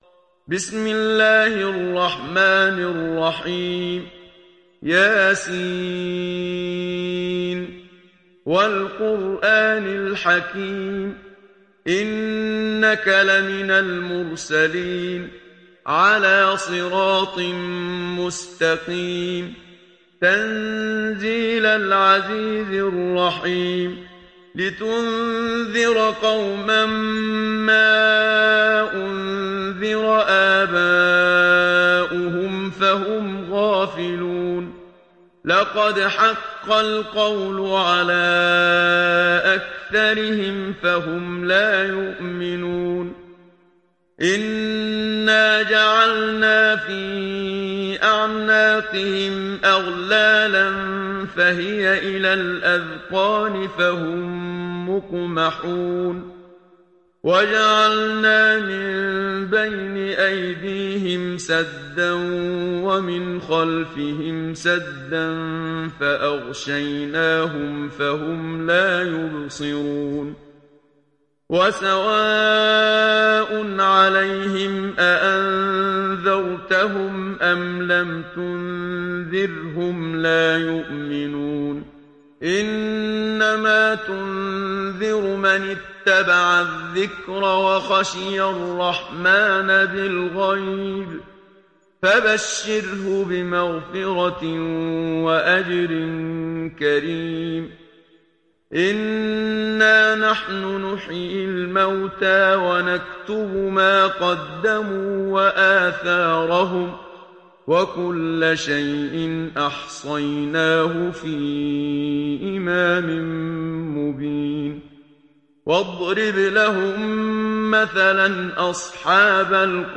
Sourate Yasin mp3 Télécharger Muhammad Siddiq Minshawi (Riwayat Hafs)